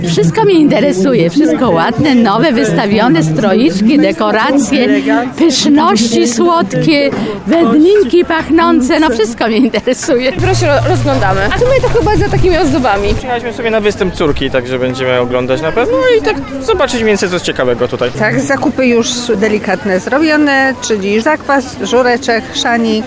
Pogoda dopisała. Na jarmarku w Lisiej Górze tłumy mieszkańców